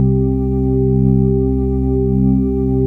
DM PAD2-69.wav